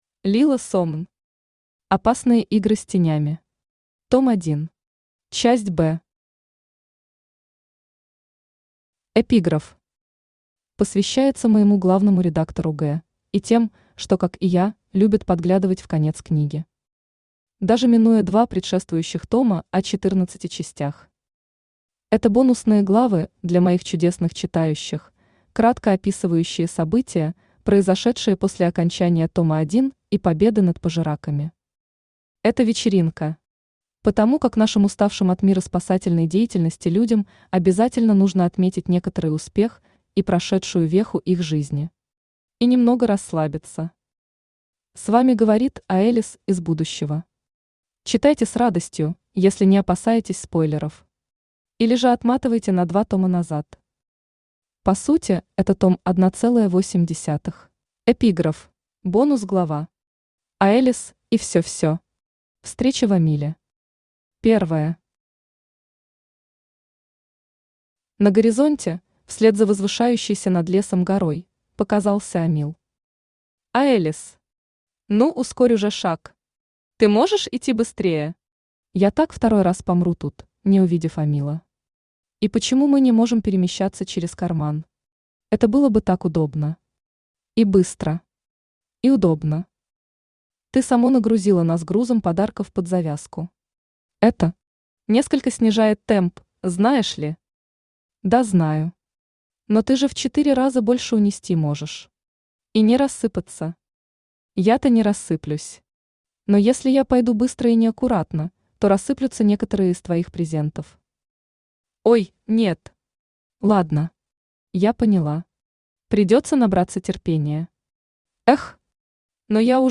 Читает: Авточтец ЛитРес